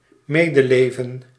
Ääntäminen
IPA : /ˈkɑndələns/